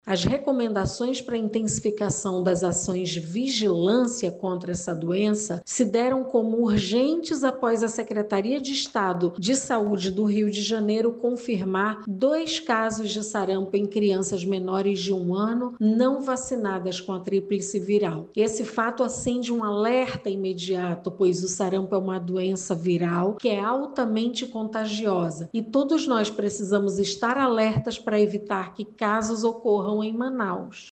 A secretária municipal de saúde, Shádia Fraxe, explica que a recomendação veio após o surgimento de novos casos da doença.